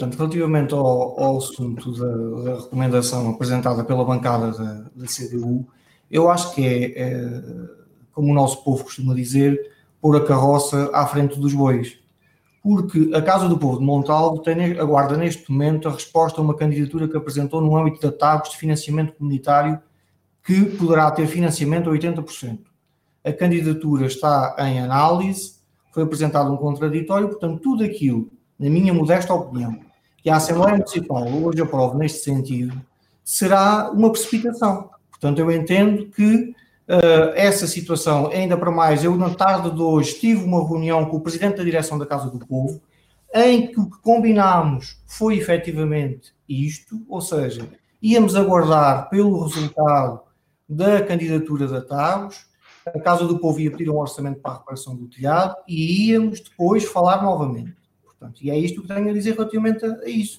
Em reação à recomendação da CDU, o presidente da Câmara Municipal de Constância, Sérgio Oliveira (PS), considerou que se está a “meter a carroça à frente dos bois”, uma vez que, no caso da Casa do Povo de Montalvo se aguarda resposta a uma candidatura feita no âmbito da TAGUS “de financiamento comunitário que poderá ter financiamento a 80%”.